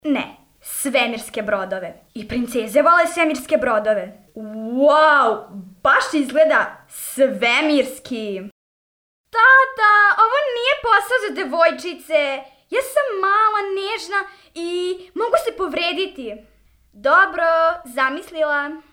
Serbian child voice over